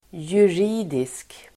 Uttal: [jur'i:disk]